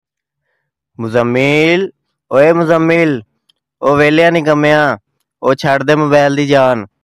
Funny Goat